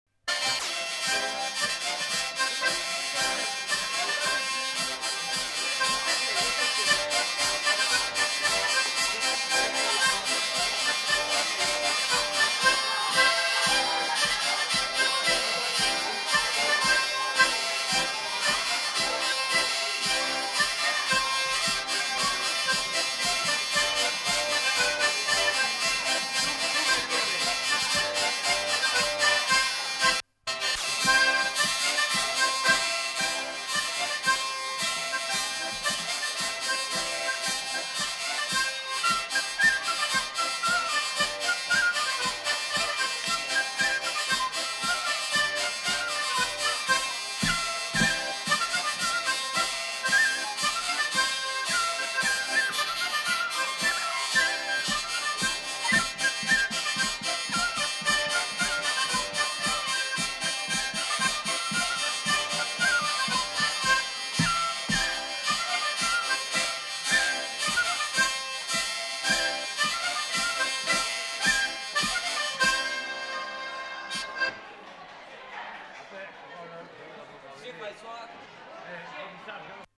Aire culturelle : Gabardan
Lieu : Houeillès
Genre : morceau instrumental
Instrument de musique : vielle à roue ; accordéon diatonique ; flûte à bec ; violon
Danse : polka piquée